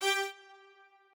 strings8_30.ogg